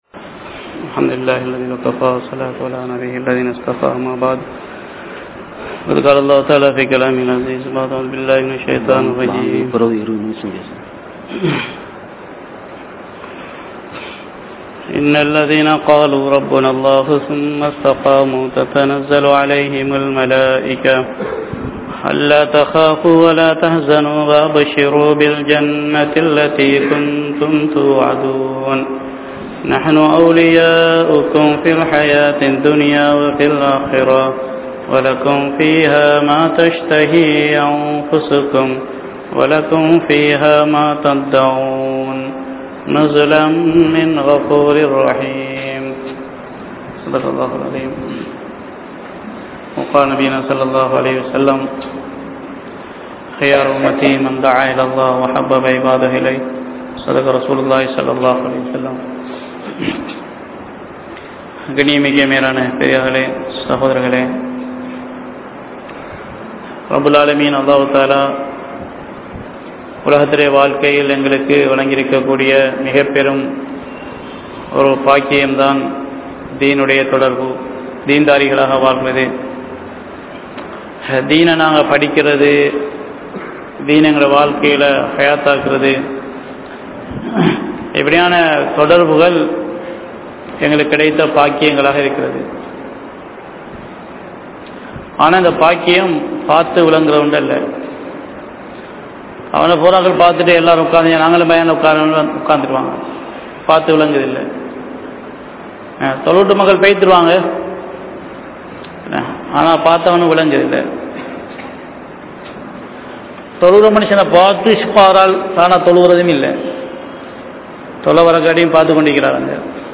Dheenin Paakkiyam (தீணின் பாக்கியம்) | Audio Bayans | All Ceylon Muslim Youth Community | Addalaichenai
Grand Jumua Masjidh(Markaz)